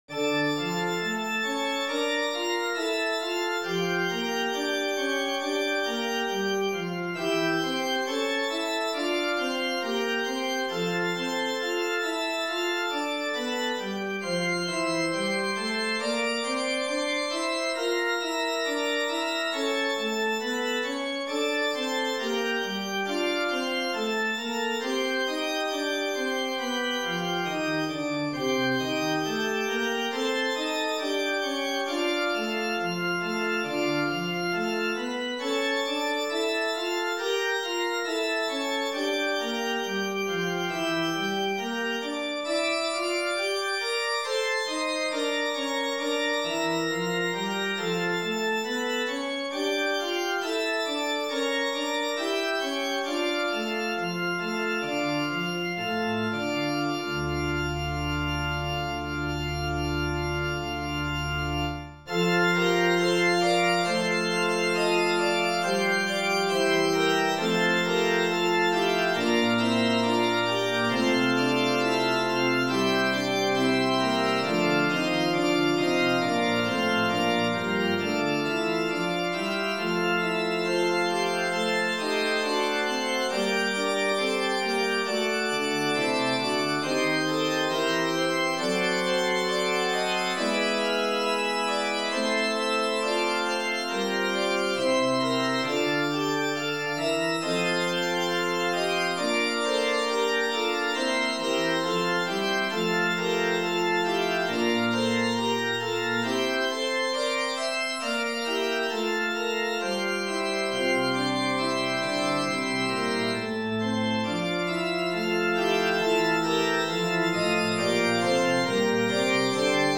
for organ